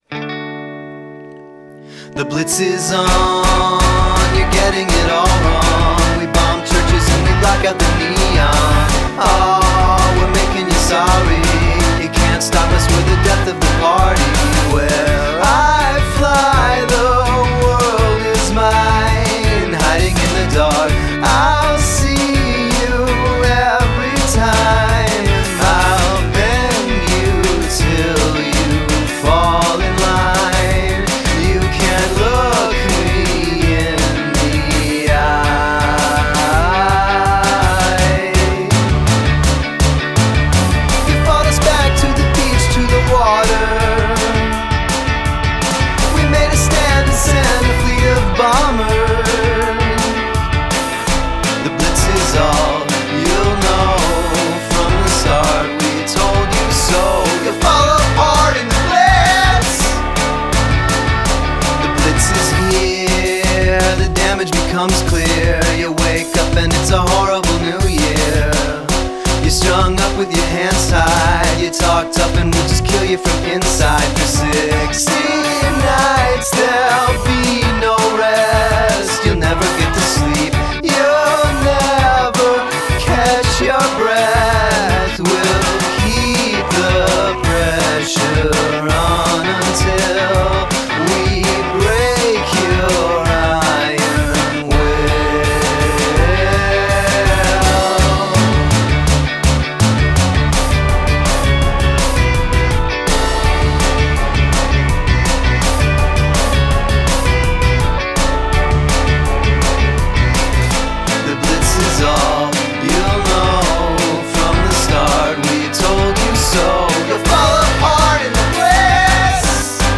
Use of choral voices